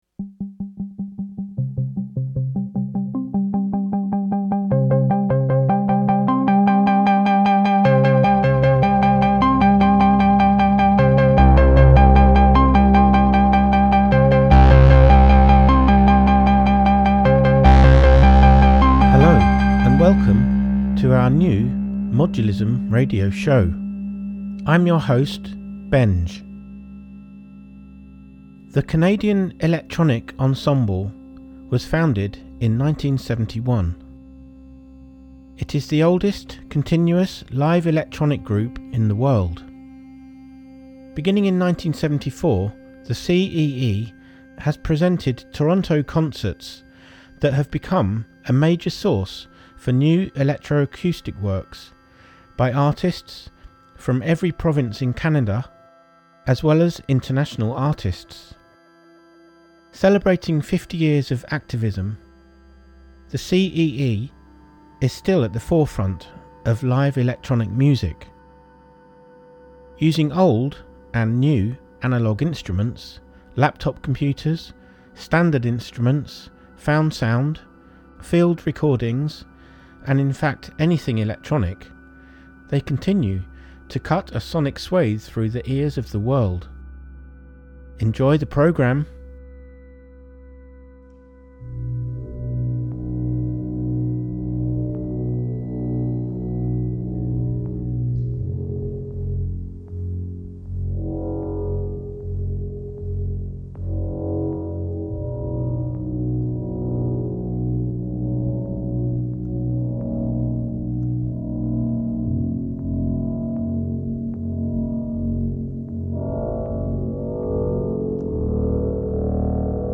A radio program airing music made using modular systems…